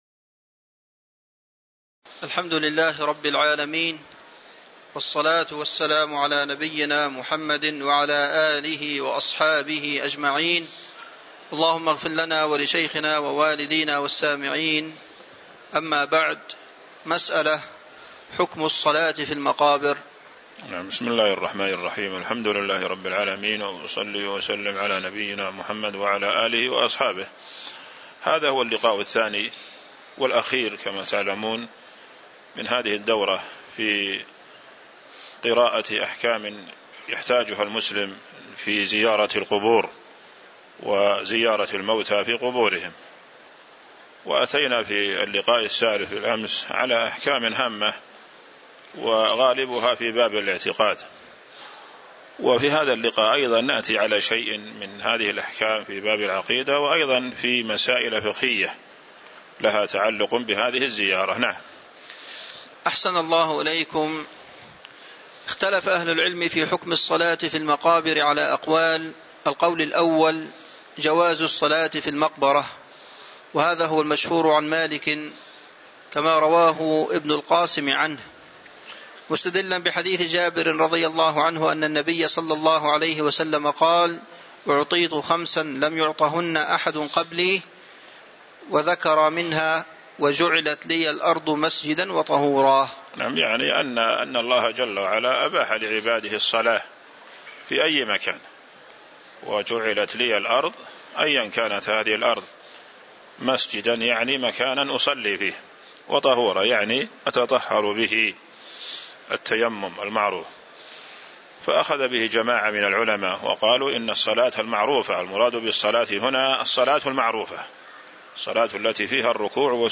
تاريخ النشر ٢١ محرم ١٤٤٦ هـ المكان: المسجد النبوي الشيخ